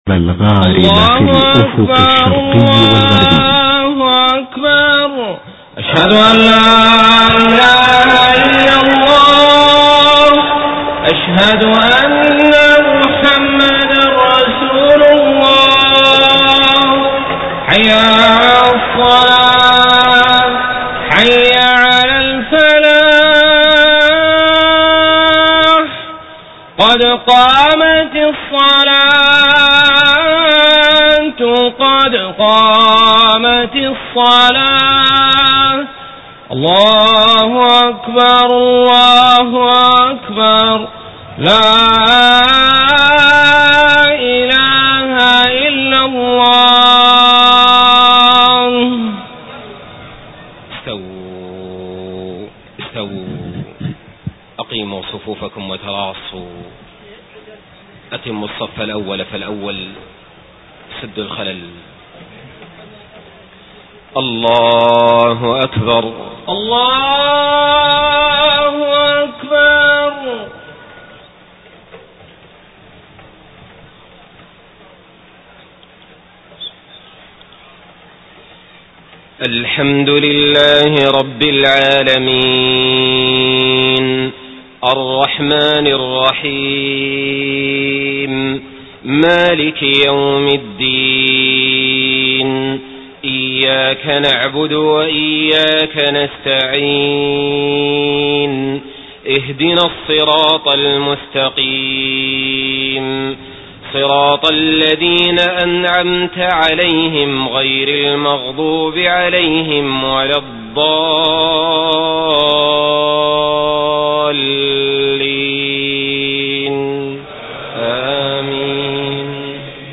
صلاة العشاء 29 صفر 1431هـ خواتيم سورة الذاريات 47-60 > 1431 🕋 > الفروض - تلاوات الحرمين